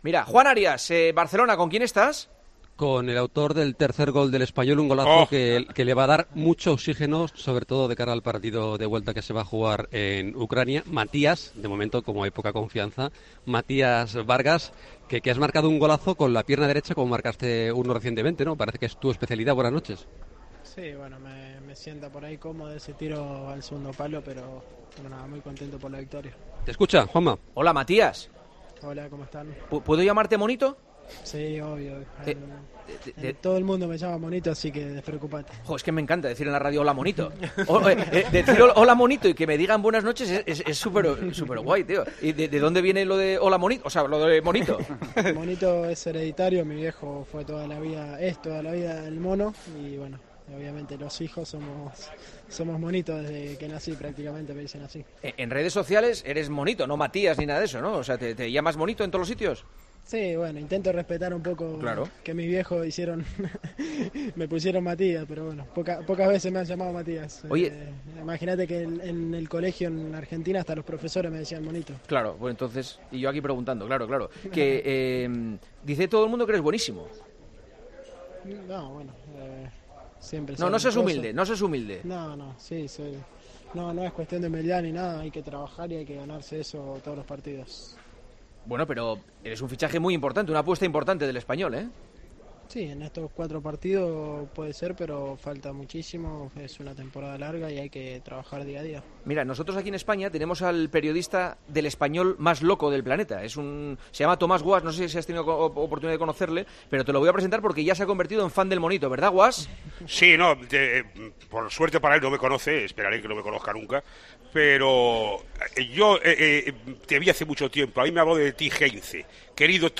AUDIO: El delantero argentino estuvo en El Partidazo de COPE analizando la victoria ante el Zorya.